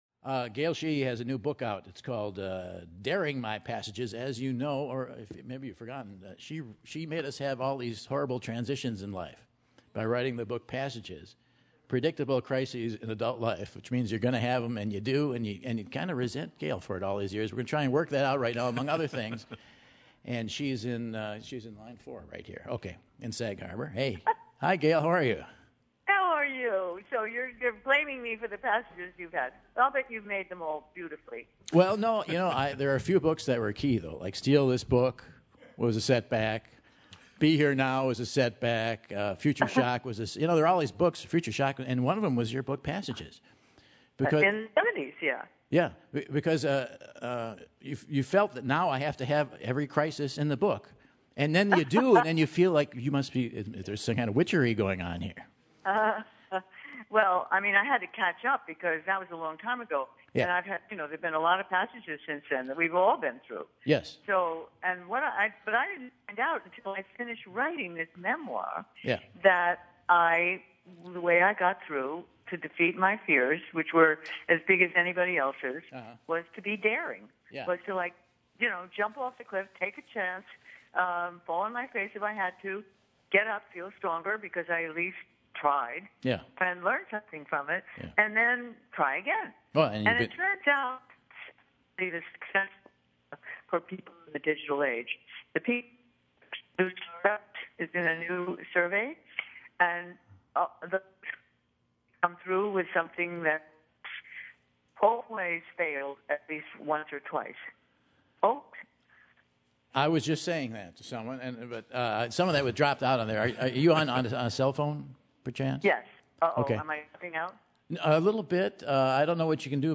Sheehy gets the call from Michael to chat about her latest work, the changes and crisises of life and more.